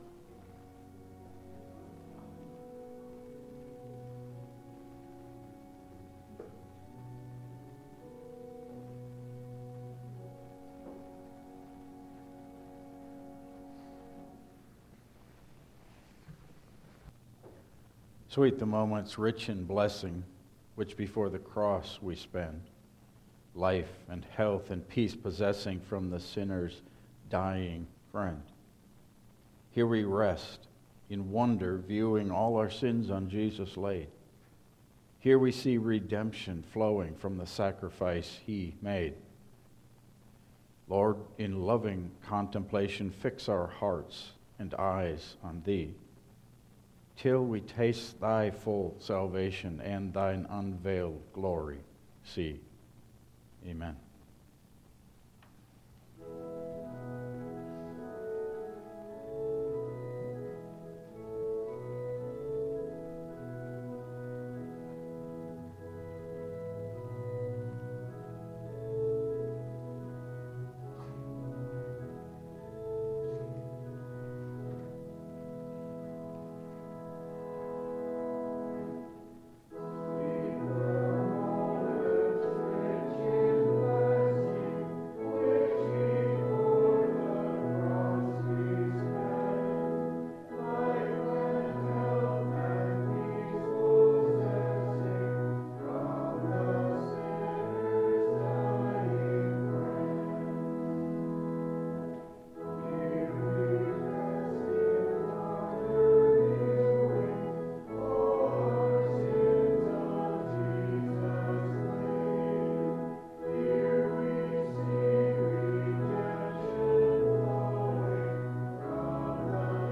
Passage: Matthew 26:57-68 Service Type: Lenten Service